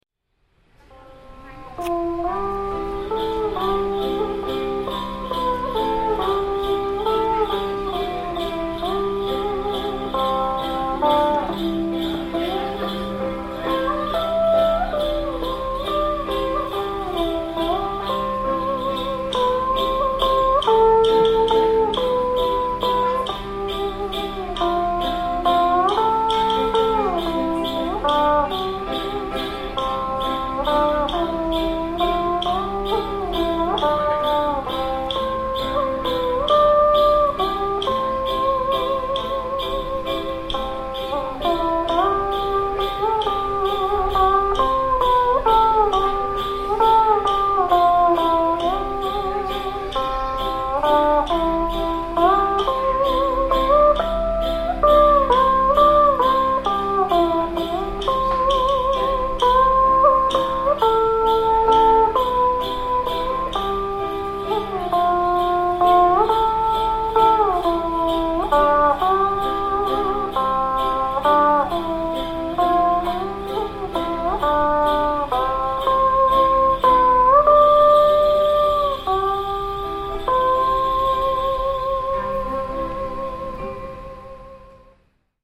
In one of it’s pavilions … traditional folk instrument performances are presented daily.
Monochord (Dan Bau) – 1.4mins
The Dan Bau’s amazing sound is like a cross between a Theremin and a steel guitar.
hanoi-temple-of-literature-folk-music-performance-monochord-3rdjune11.mp3